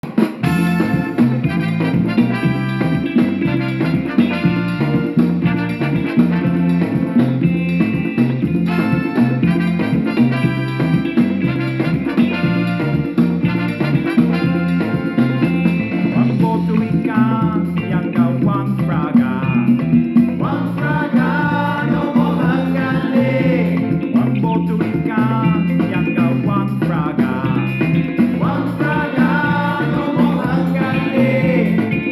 These exotic Afro-Latin rhythms make you feel like dancing!